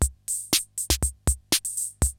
CR-68 LOOPS3 4.wav